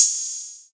Open Hats
OH - Drumma.wav